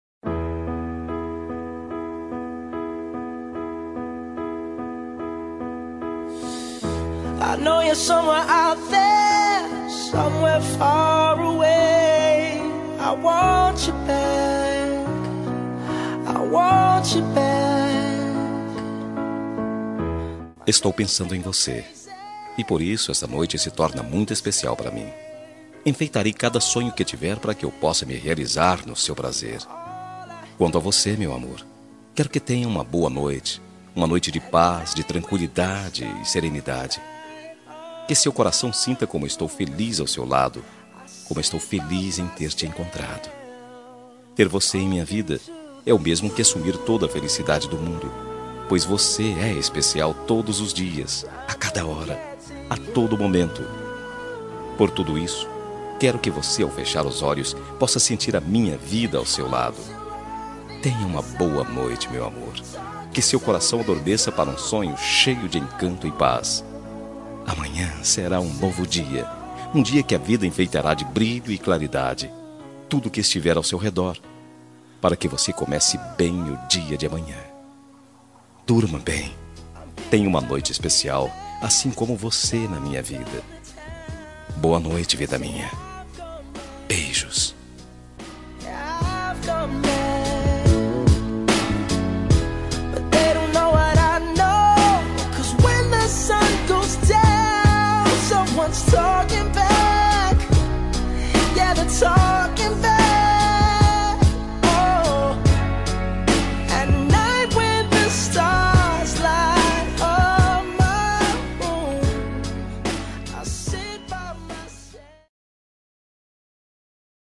Temas com Voz Masculina